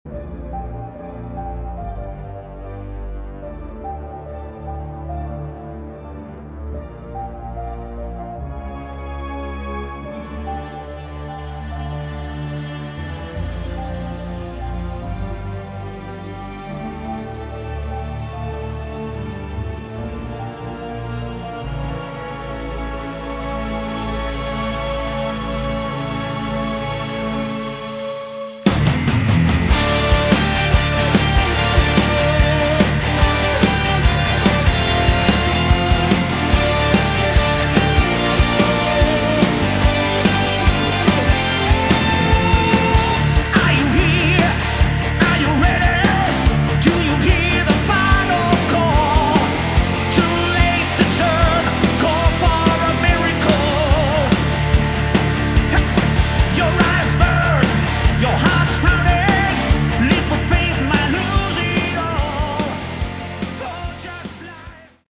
Category: Melodic Metal
bass
keyboards
vocals
guitar
drums